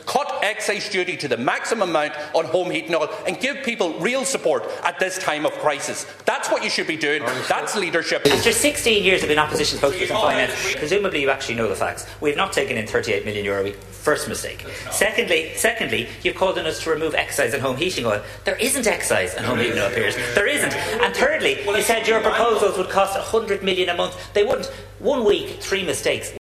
The Government and opposition have clashed over energy prices on the last day of the Dáil before the Easter recess.
But Tánaiste Simon Harris says parts of Sinn Féin’s own plan are works of fiction……………..